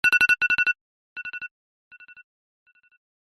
Apple 'Radar' tone.